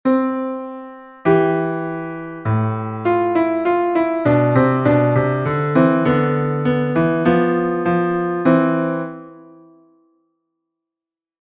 So ist zum Beispiel in nachstehendem kanonischen Sätzchen die zweite Stimme nichts anderes als die in der Gegenbewegung dargestellte erste. Jeder Schritt, den die Proposta aufwärts macht, wird von der Risposta mit einem gleichweiten Schritt abwärts beantwortet - und umgekehrt: